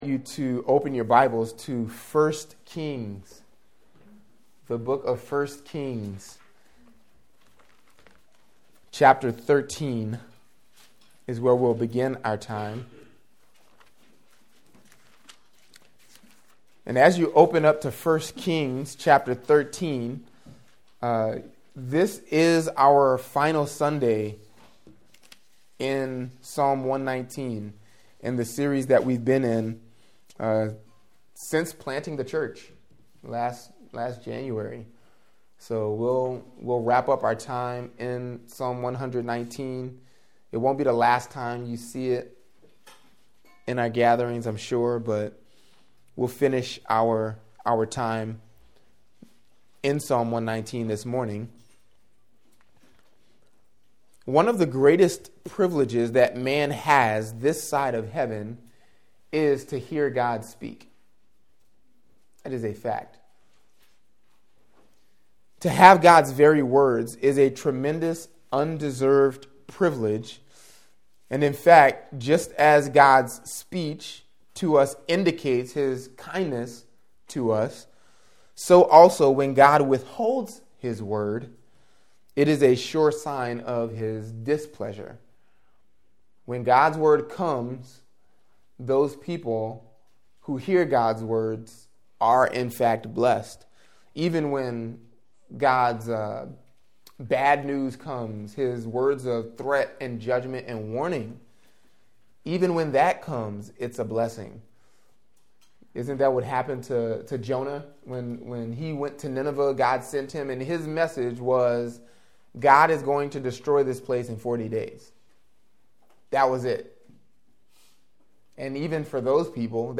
Podcast (gbc-nola-sermons): Play in new window | Download